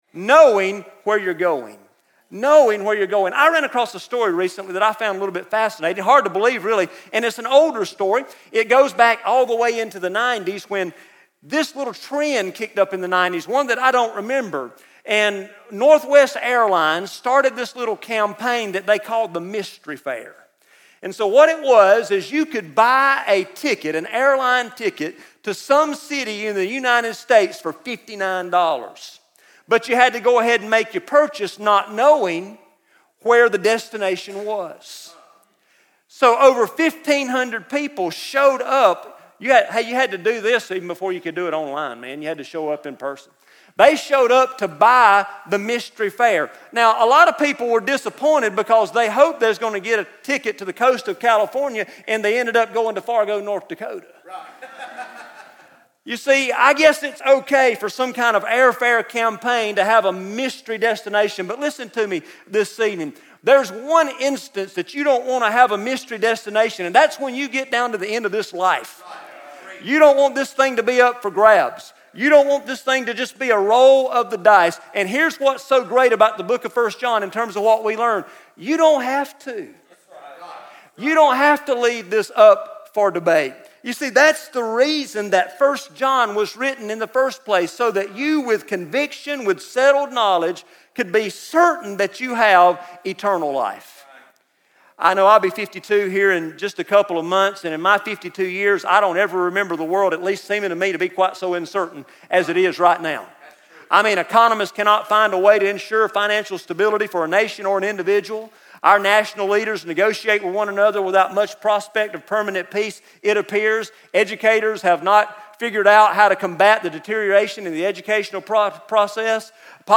Message #10 from the ESM Summer Camp sermon series through the book of First John entitled "You Can Know"